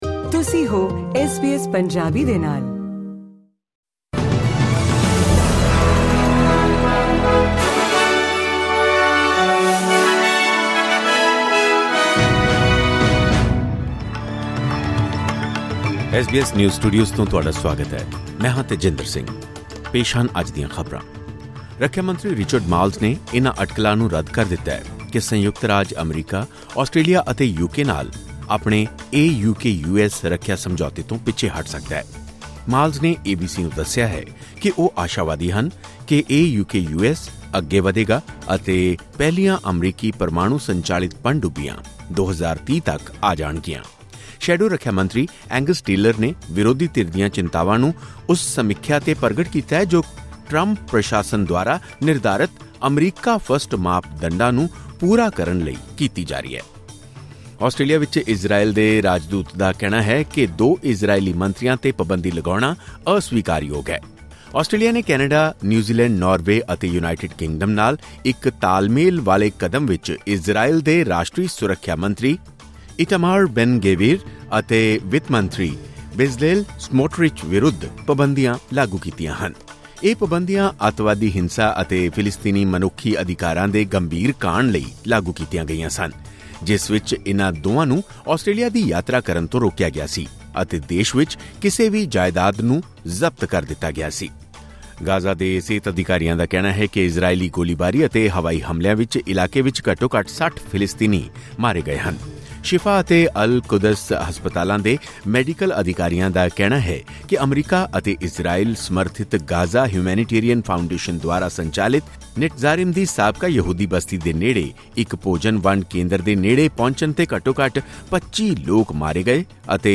ਖਬਰਨਾਮਾ: ਰੱਖਿਆ ਮੰਤਰੀ ਰਿਚਰਡ ਮਾਰਲਸ ਨੇ AUKUS ਸਮਝੌਤੇ ਦੇ ਭਵਿੱਖ 'ਤੇ ਸ਼ੰਕਾਵਾਂ ਨੂੰ ਕੀਤਾ ਰੱਦ